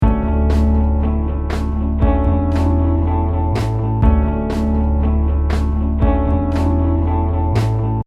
Now a kick and snare have been added. Matching the loop’s beat.